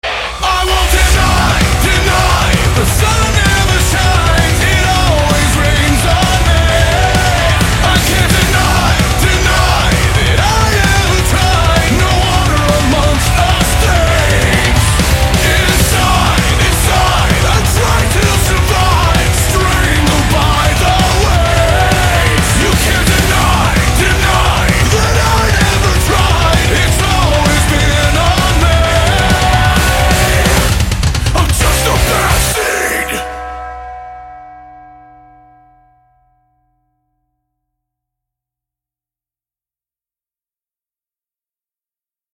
• Качество: 128, Stereo
громкие
мощные
брутальные
Драйвовые
Alternative Metal
nu metal